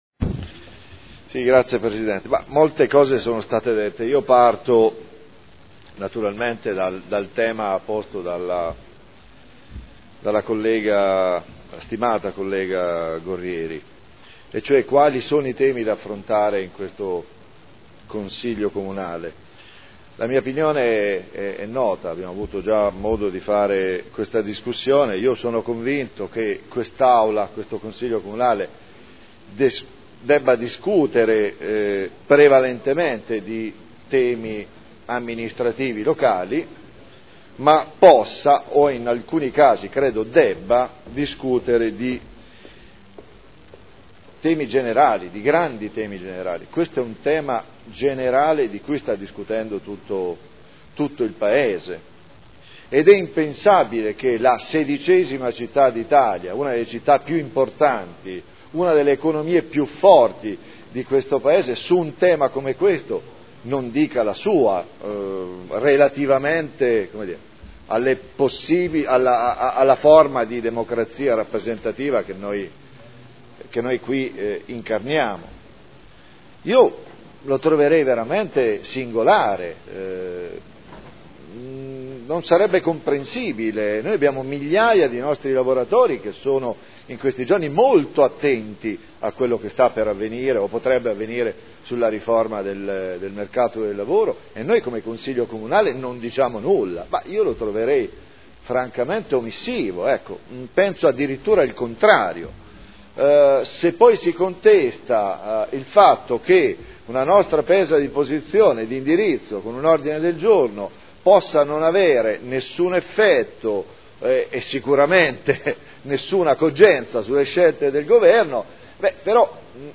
Paolo Trande — Sito Audio Consiglio Comunale
Mozione presentata dai consiglieri Ricci (Sinistra per Modena) e Trande (P.D.) avente per oggetto: “Riforma del “mercato del lavoro” e Articolo 18 dello Statuto dei Lavoratori: diritti dei lavoratori, delle lavoratrici e relazione con la crescita e occupazione” Dibattito